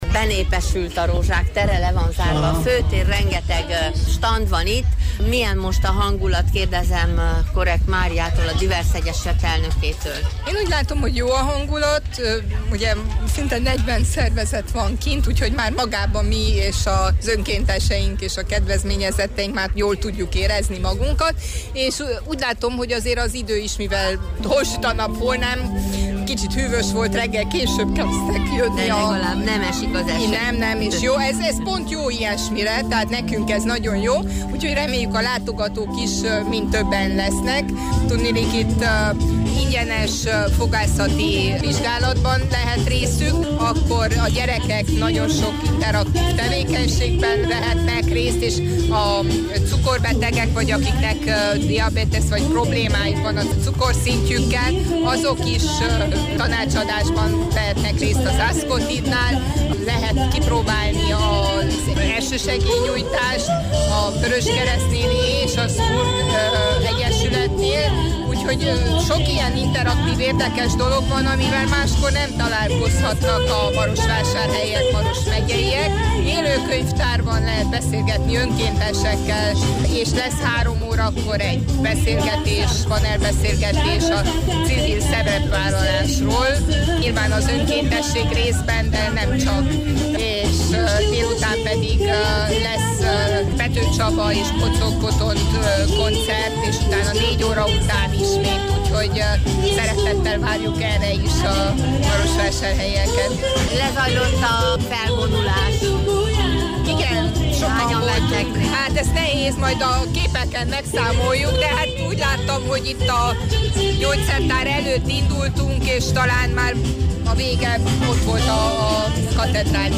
Idén is megrendezésre került Marosvásárhelyen a Civil fórum és a Fussunk együtt a közösségért elnevezésű civil cross, versenyfutás, valamint az Együtt a közösségért nevű civil vásár.